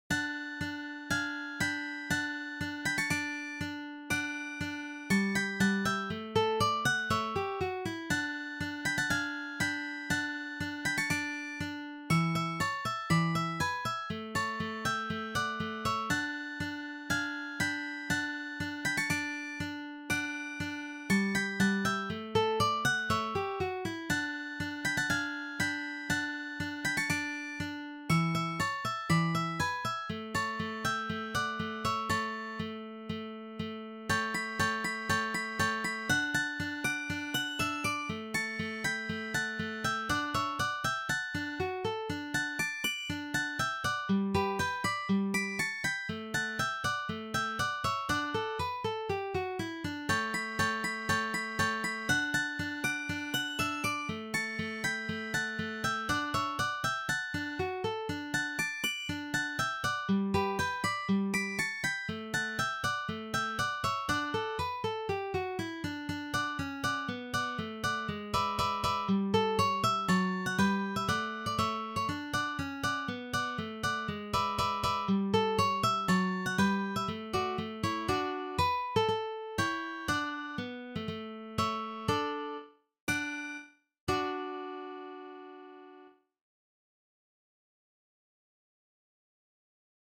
for three guitars
This is from the Classical period.